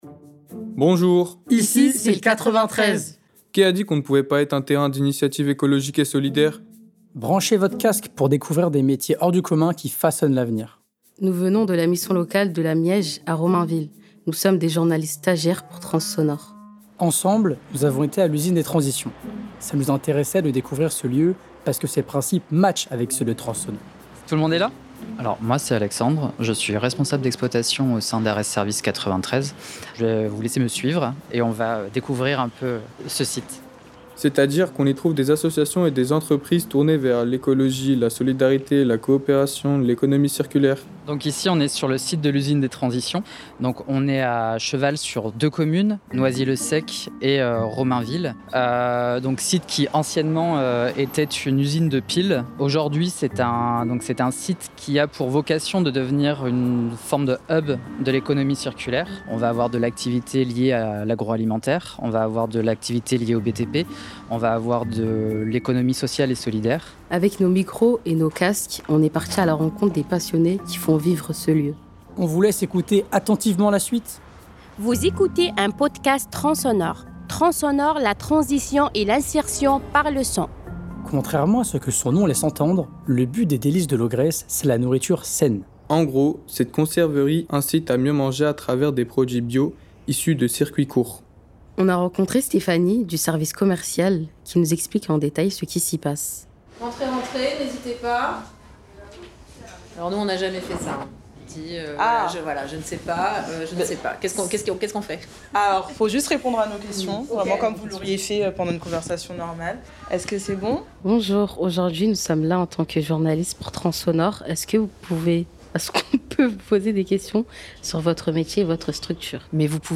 Nous sommes des journalistes stagiaires pour transonore et venons de la Mission Locale de la MIEJ à Romainville.
Avec nos micros et nos casques, on est parti à la rencontre des passionnées qui font vivre l’association.